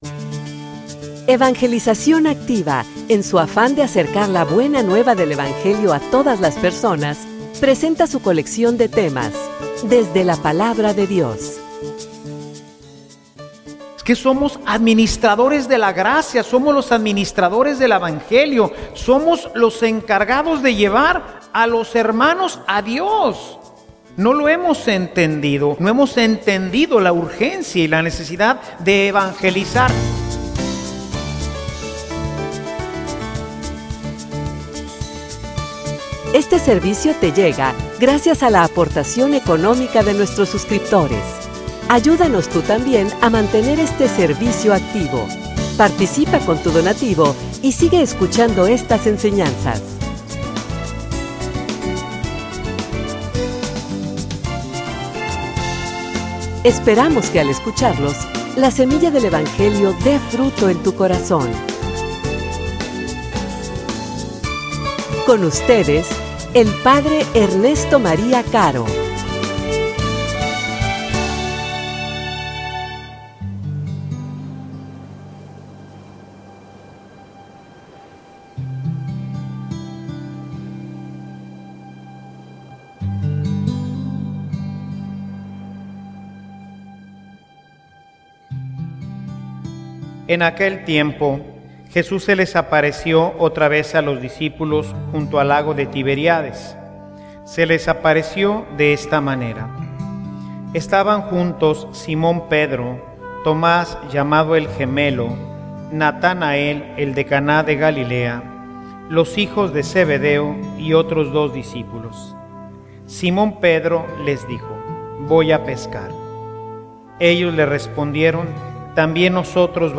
homilia_Pastorea_a_tu_familia.mp3